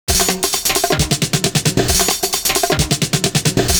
SOJA FILL.wav